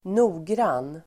Uttal: [²n'o:gran:]